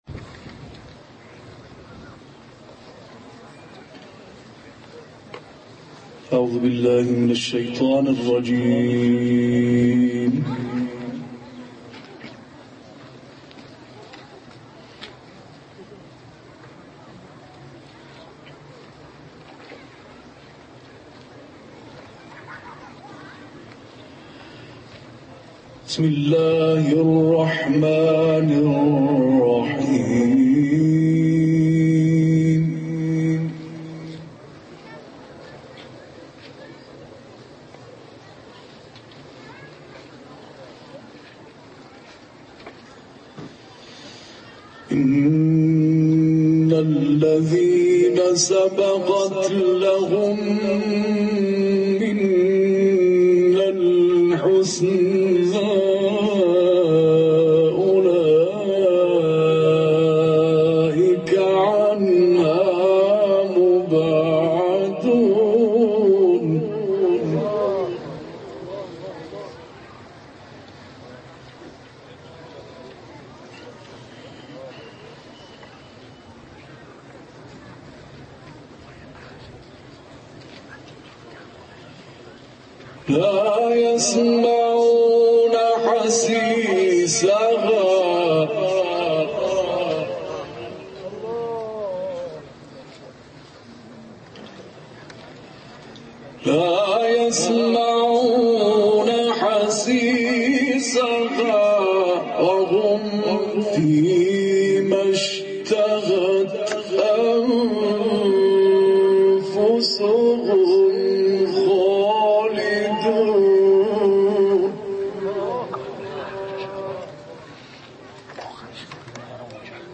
قاری بین‌المللی کشورمان در جدیدترین تلاوتش آیات 101 تا 104 سوره مبارکه انبیاء را تلاوت کرد.